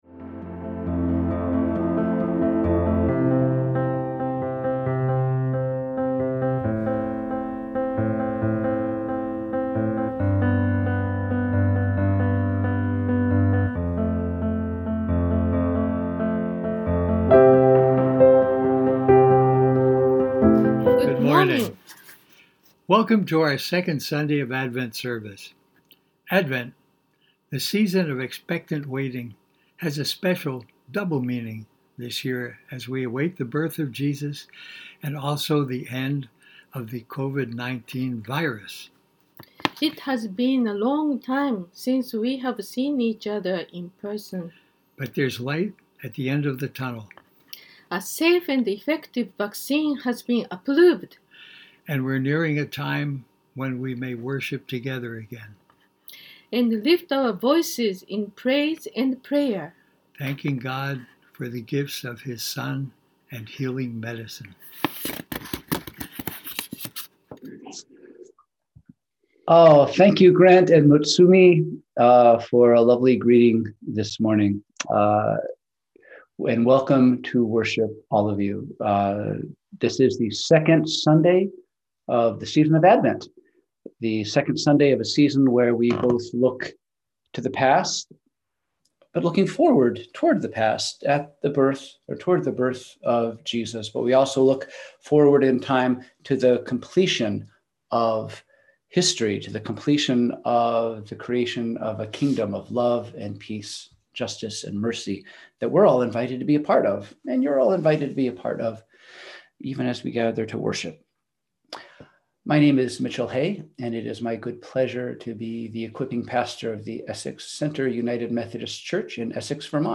We held virtual worship on Sunday, December 6, 2020 at 10:00AM.
Sermons